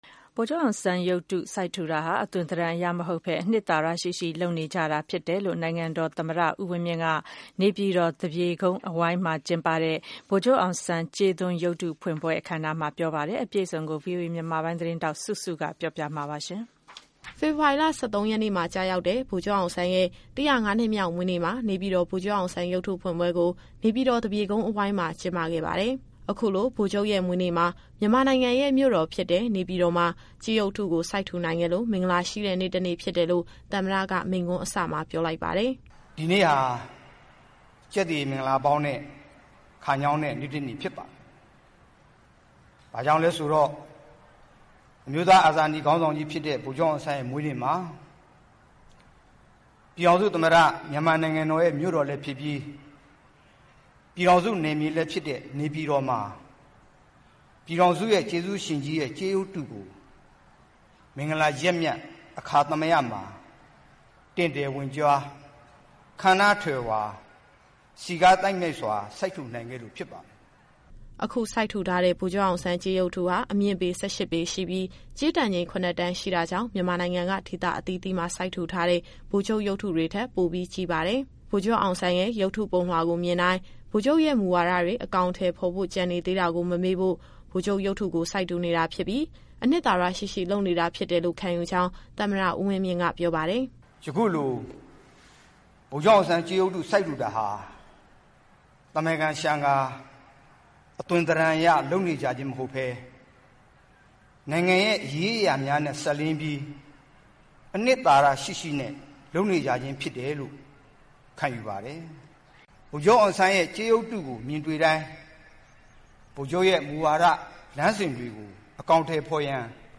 နေပြည်တော် ဗိုလ်ချုပ်ကြေးရုပ်ဖွင့်ပွဲ သမ္မတ တက်ရောက်မိန့်ခွန်းပြော
ဗိုလ်ချုပ်အောင်ဆန်းကြေးရုပ်တုကို စိုက်ထူတာဟာ အသွင်သဏ္ဍာန်အရ လုပ်ဆောင်တာမဟုတ်ဘဲ အနှစ်သာရရှိရှိ လုပ်နေကြတာဖြစ်တယ်လို့ နိုင်ငံတော်သမ္မတ ဦးဝင်းမြင့်က နေပြည်တော် သပြေကုန်းအဝိုင်းမှာ ကျင်းပတဲ့ ဗိုလ်ချုပ်အောင်ဆန်းကြေးရုပ်တု ဖွင့်ပွဲအခမ်းအနားမှာ ပြောဆိုလိုက်တာပါ။